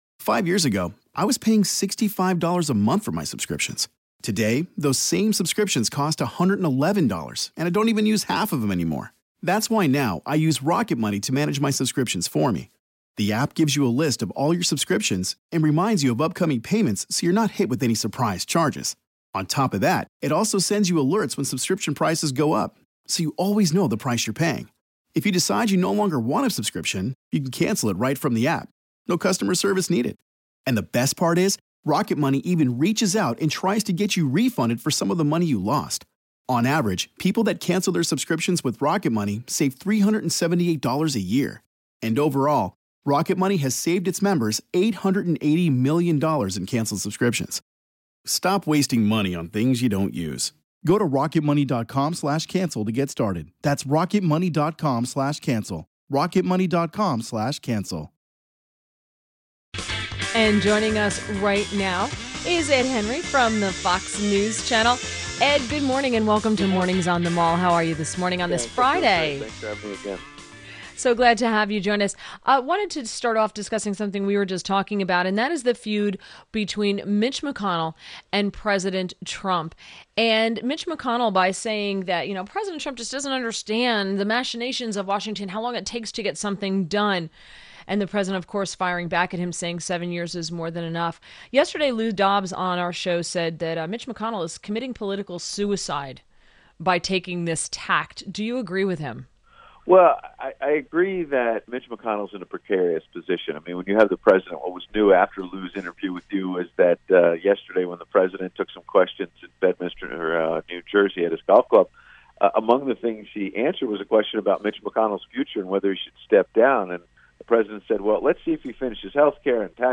WMAL Interview - ED HENRY - 08.11.17